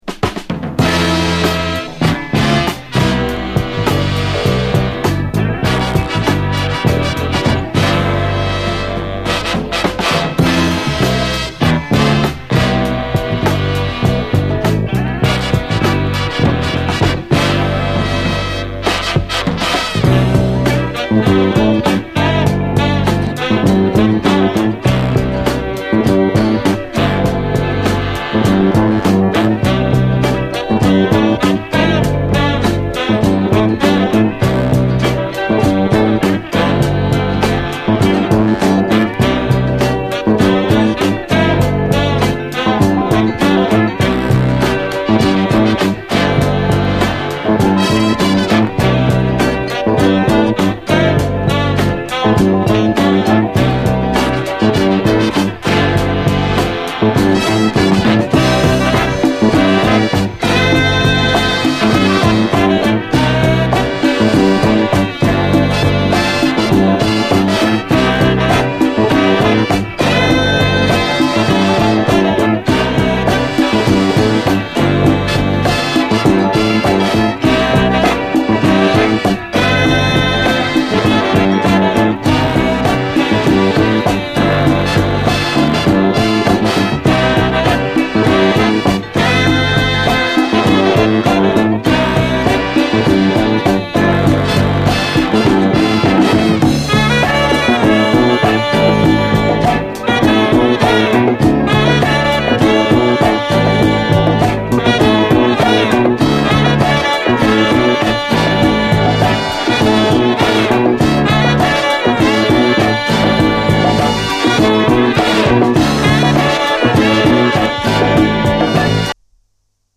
SOUL, 70's～ SOUL, DISCO
謎めいたインスト・ソウル・アルバム！
ブラス隊含むオーケストラ・アレンジがゴージャスで